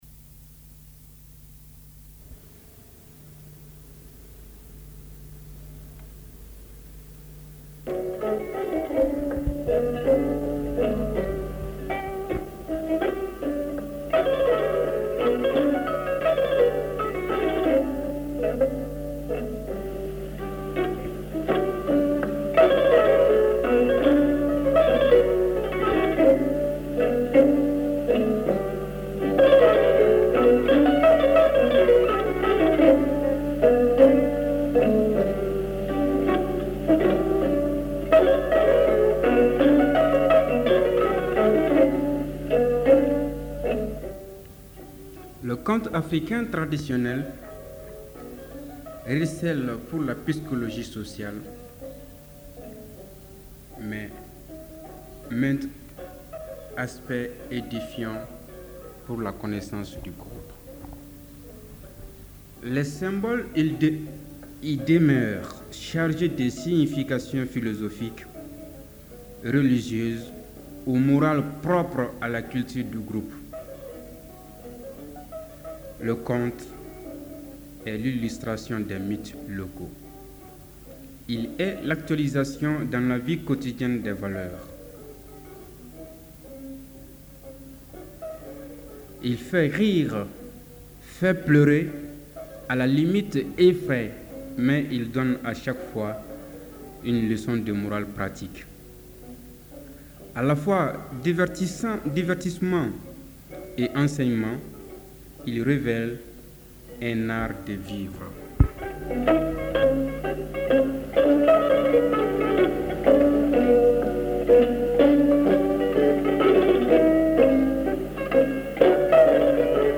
Conte malien